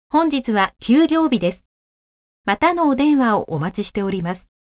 【アナウンスサービス　メッセージ一覧】
■アナウンスサービス４